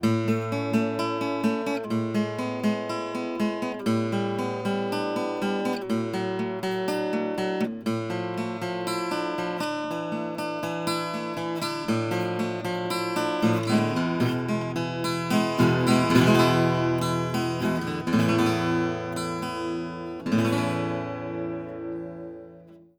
オン・マイクではサウンド・ホールから30cm程度の距離に、オフ・マイクでは1m程度の距離にセットしています。
アルペジオ/オン・マイク
acousticG_arpeggio_on.wav